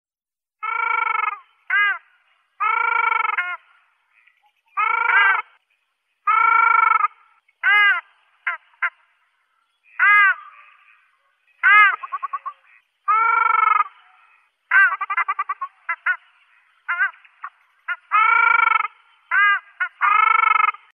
Звуки диких уток в природе